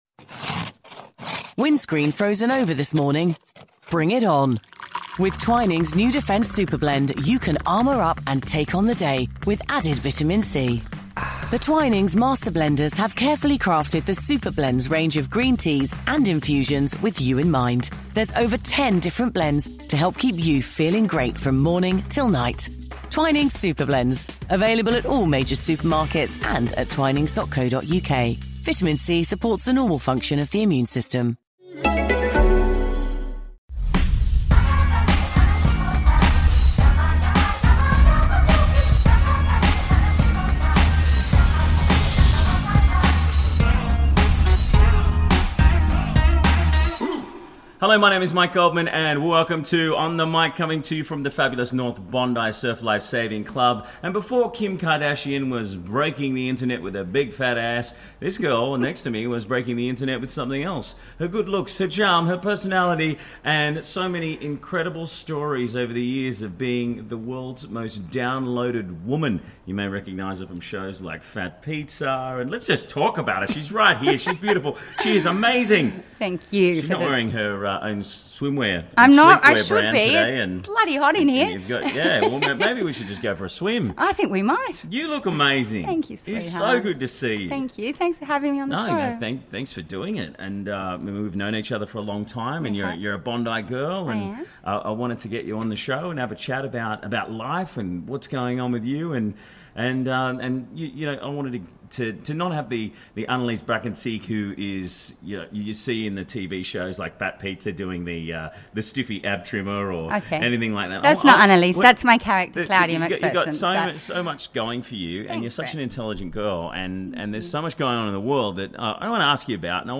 Now the media has settled and her family and close friends have opened up about this tragedy, I decided to release this never before seen interview of a beautiful friend who we lost too soon. Recorded at Bondi surf club a few months before her death.